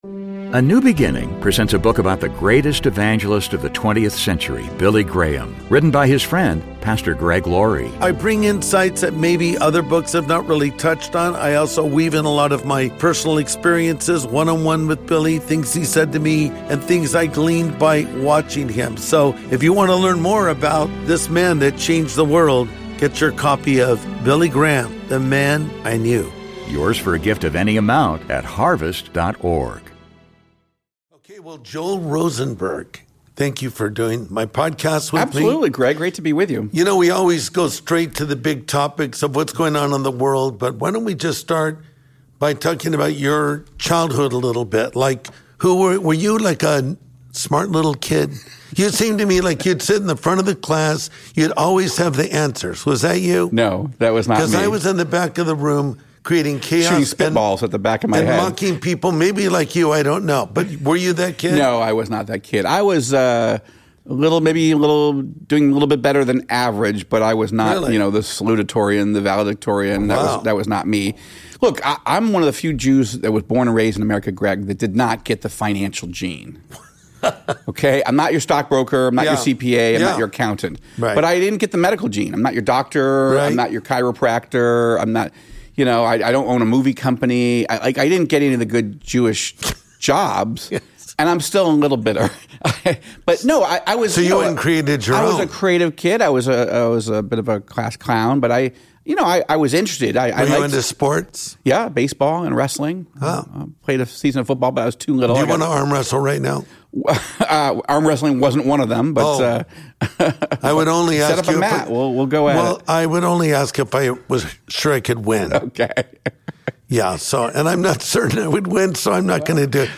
Together, he and Pastor Greg Laurie break down the problems with Replacement Theology, why Christians should care about Israel, and end times prophecy. This insightful sit-down shares the answers to questions on many Christians' minds.